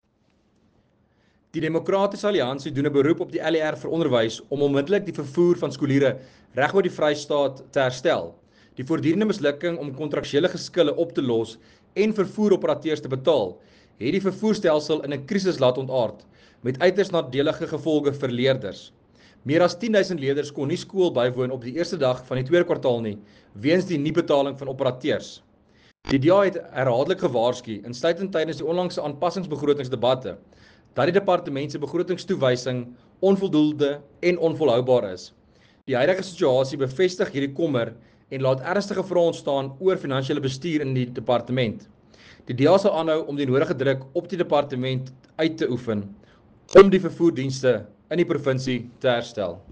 Afrikaans soundbite by Werner Pretorius MPL.